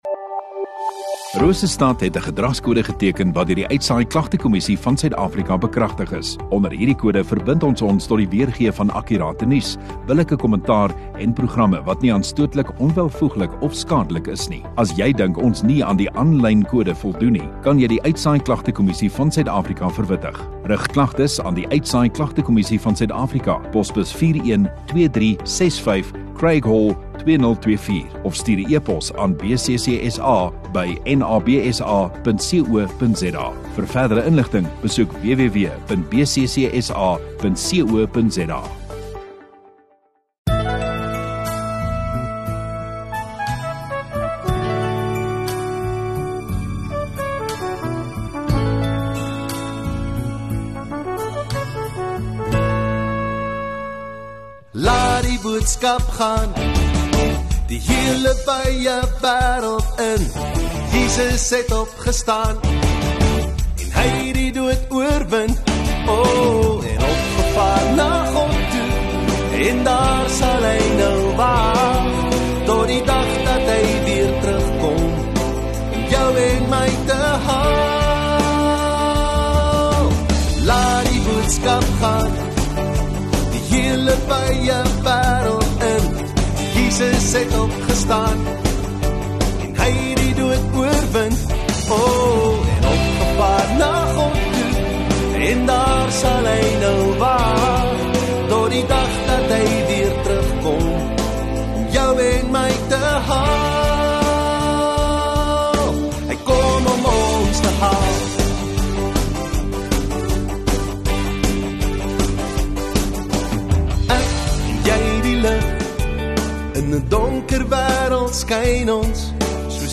14 Oct Saterdag Oggenddiens